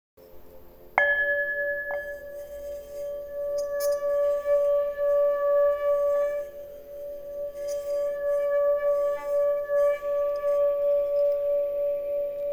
Bol tibétain - La boutique de Miss Larimar
Bol tibétain traditionnel en 7 métaux.
Note : Si.
Maillet en bois et feutre inclus.